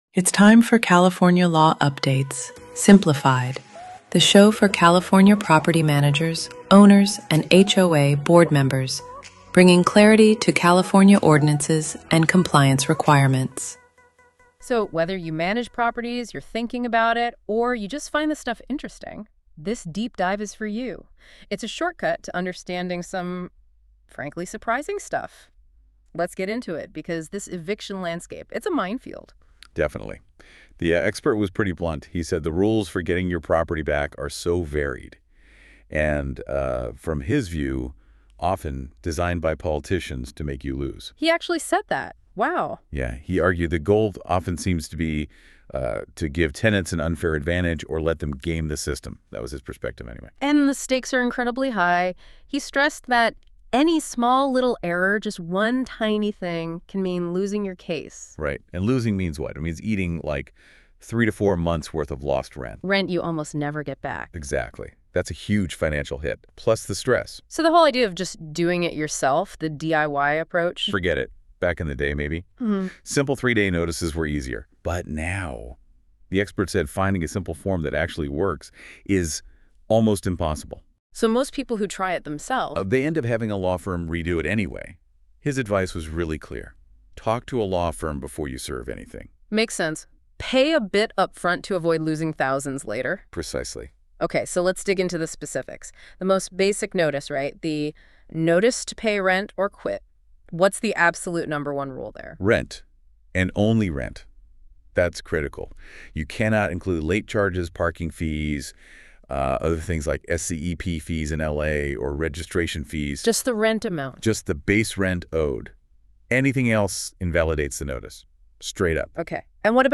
In this fast, plain-English deep dive, multi-national attorneys walk through the hyper-technical eviction rules that are tripping owners up, then pivot to balcony/EEE compliance under SB 721 (apartments) and SB 326 (condos/HOAs).